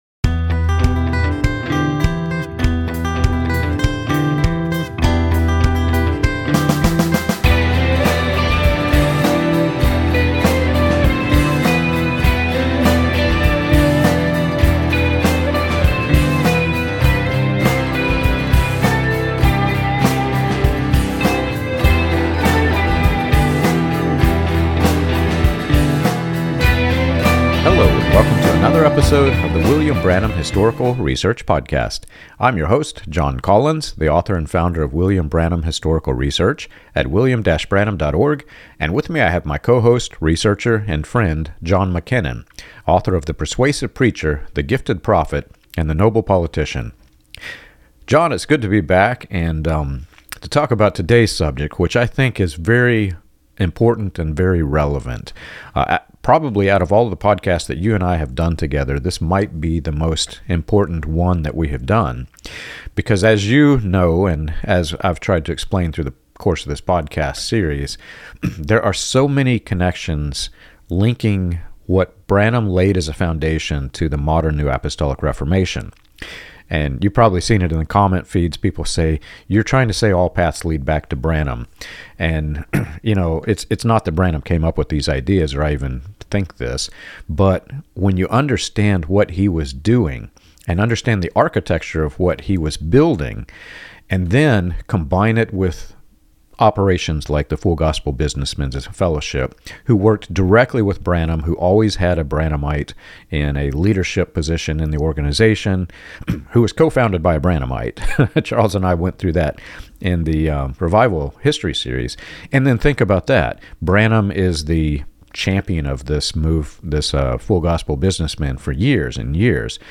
The discussion contrasts Paul's clear teaching on adoption as a finished act in Christ with Branham's two-stage model that promotes elite believers, future authority, and spiritual hierarchy. The episode also addresses the psychological and spiritual harm this framework causes, especially for survivors of high-control movements, and explains why these ideas continue to resurface in modern charismatic and apostolic streams.